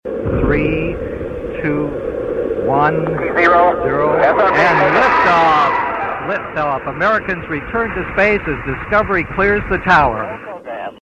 STS-26 liftoff